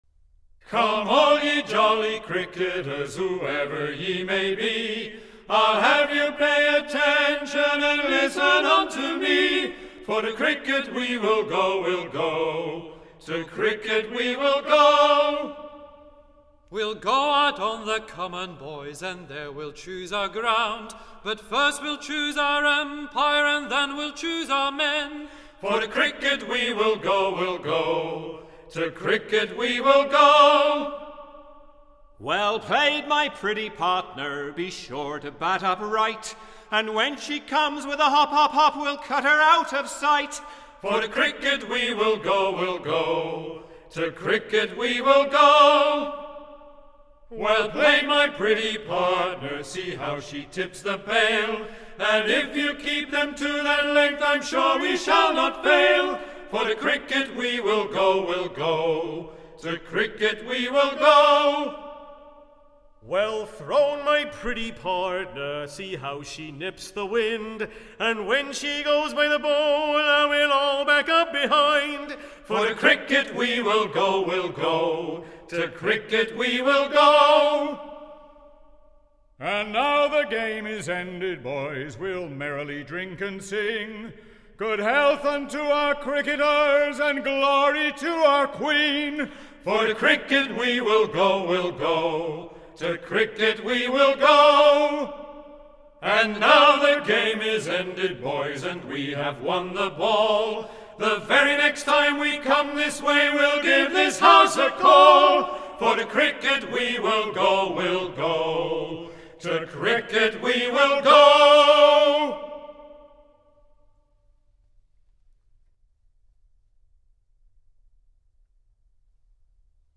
Radnage-Cricket-Song-Bucks.-folk-song1.mp3